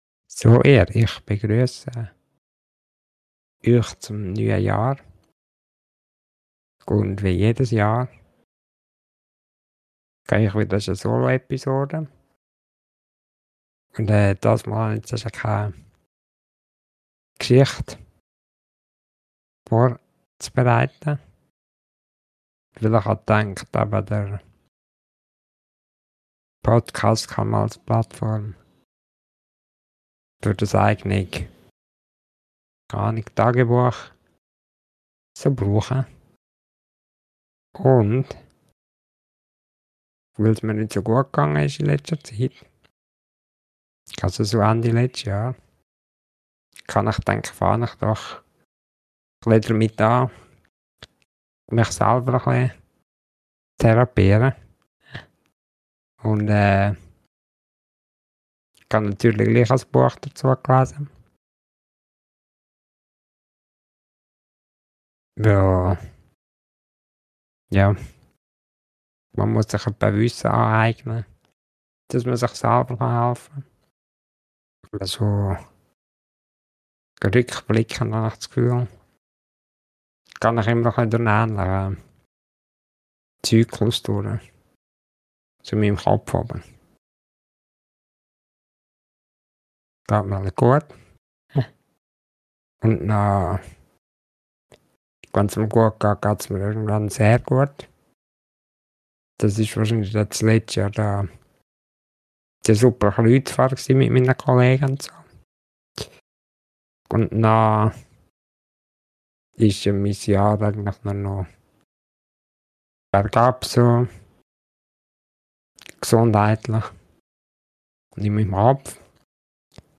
Eine kurze Soloepisode über Emotionen und Tageslichtlampe.